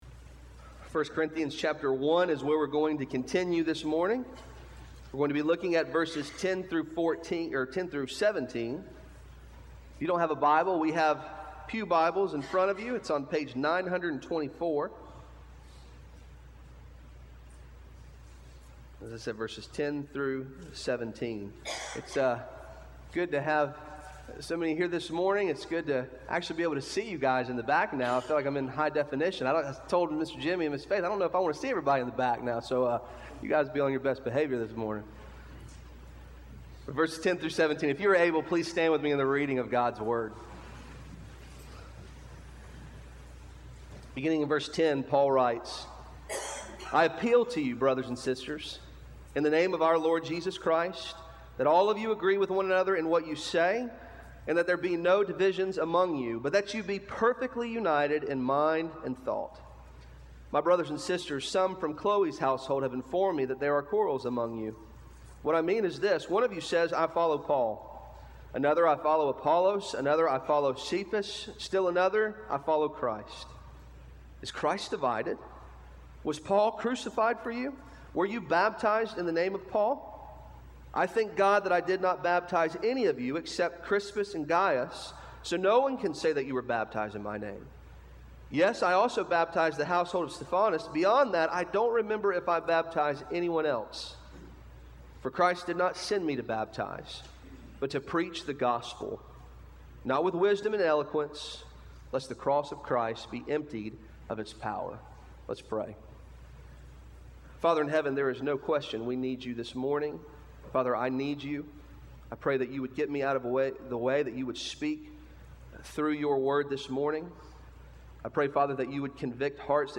Arlington Baptist Church Sermons
SU-Jan-21-18 Sermon.mp3